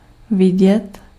Ääntäminen
France (Paris): IPA: [vwaʁ]